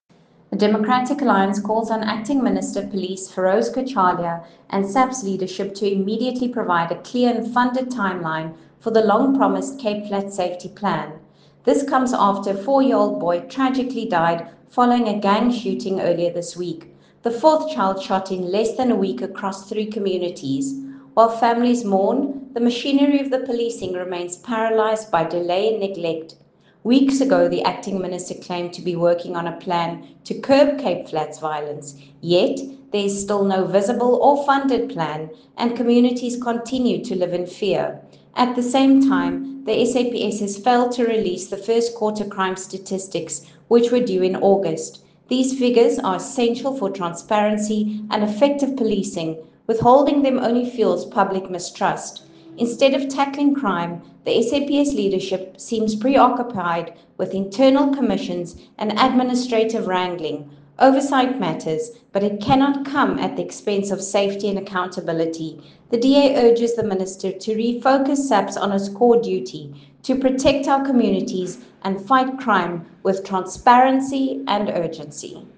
Afrikaans soundbite by Lisa Schickerling MP.